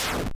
deathheavy.wav